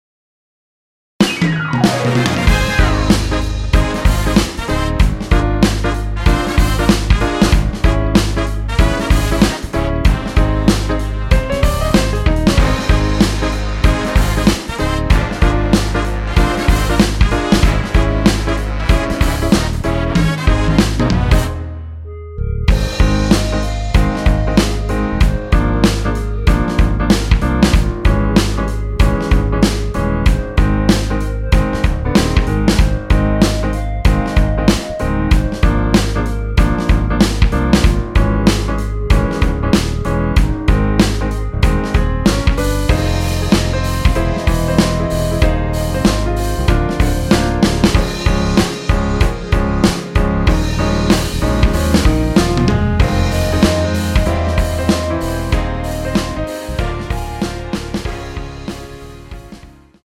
원키에서(-6)내린 멜로디 포함된 MR입니다.(미리듣기 확인)
Ab
앞부분30초, 뒷부분30초씩 편집해서 올려 드리고 있습니다.
중간에 음이 끈어지고 다시 나오는 이유는